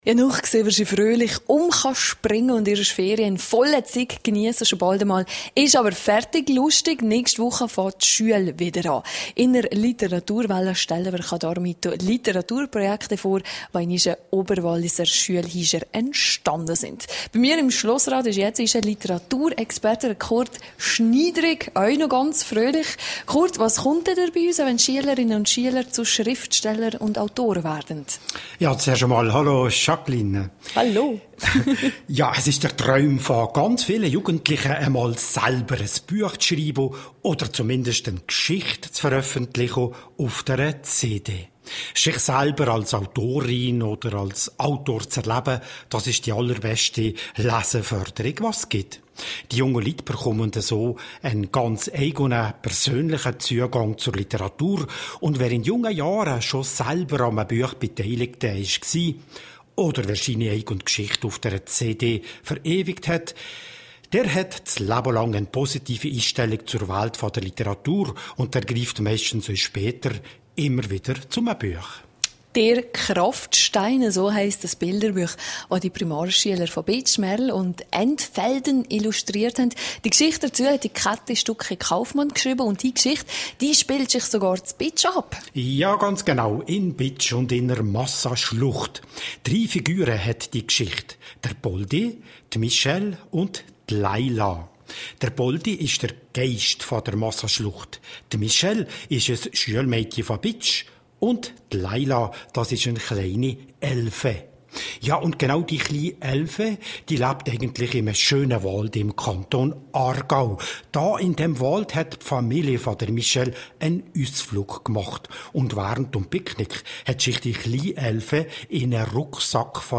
Kurz vor Schulbeginn stellen wir in der heutigen Sendung Literatur-Projekte vor, die in den Oberwalliser Schulhäusern entstanden sind.